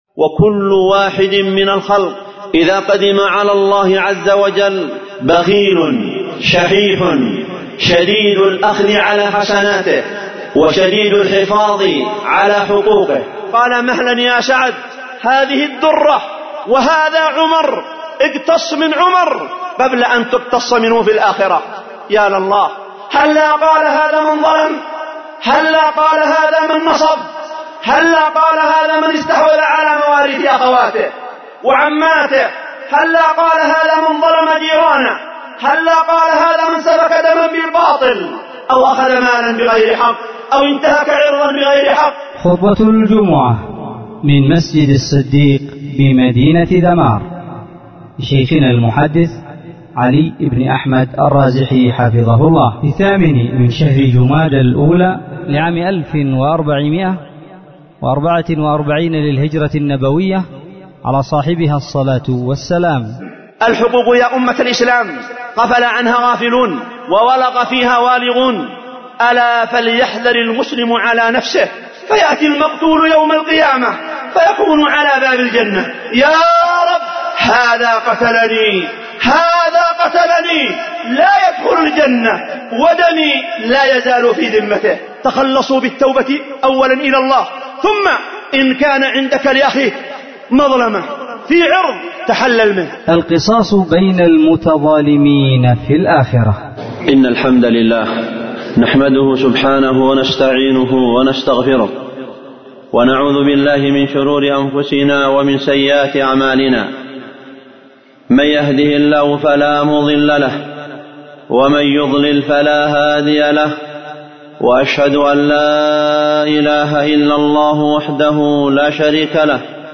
خطبة جمعة من مسجد الصديق بمدينة ذمار